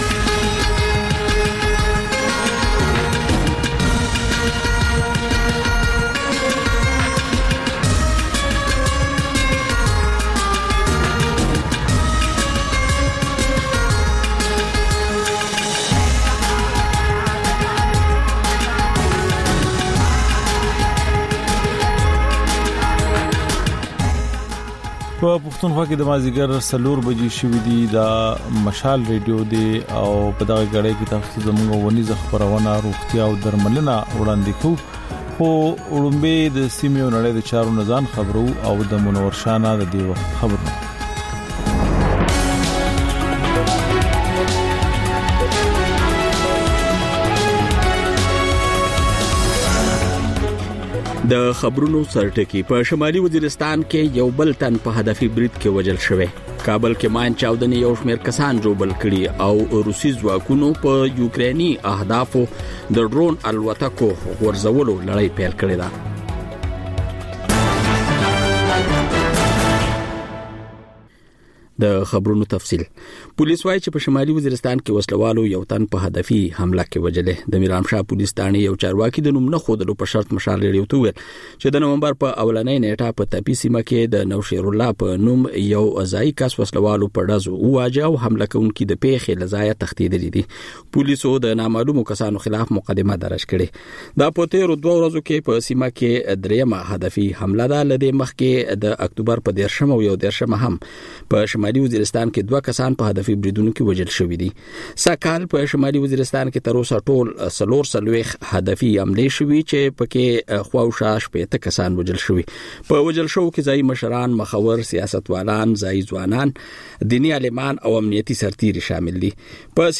د مشال راډیو مازیګرنۍ خپرونه. د خپرونې پیل له خبرونو کېږي، بیا ورپسې رپورټونه خپرېږي. ورسره اوونیزه خپرونه/خپرونې هم خپرېږي.